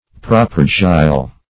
Search Result for " propargyl" : The Collaborative International Dictionary of English v.0.48: Propargyl \Pro*par"gyl\, n. [Propinyl + Gr.